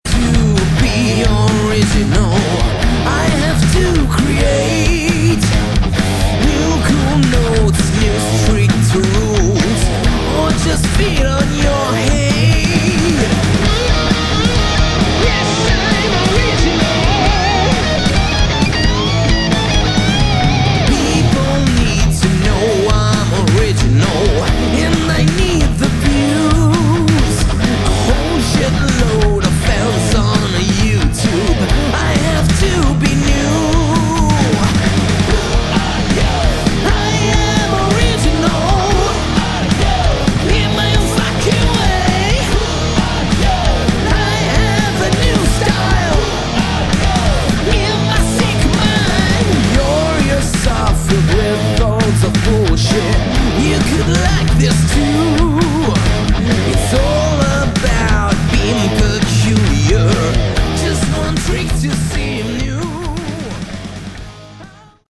Category: Hard Rock
vocals
guitars
bass
drums